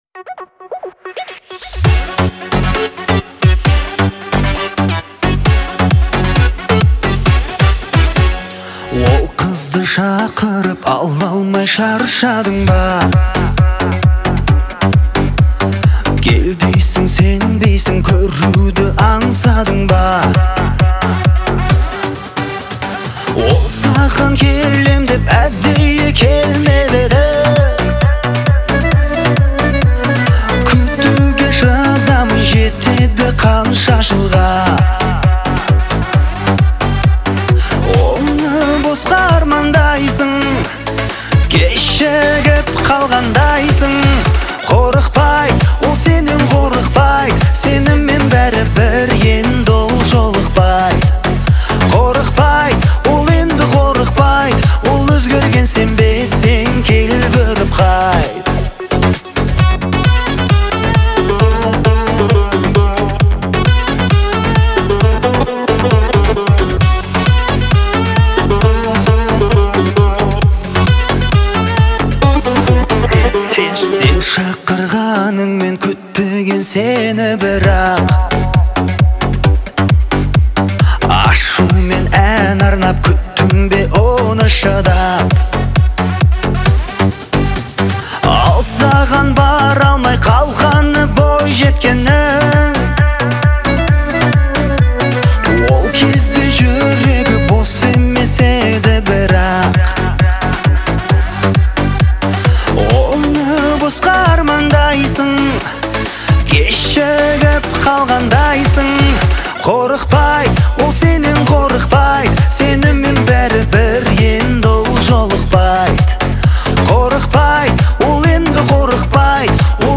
динамичная и энергичная песня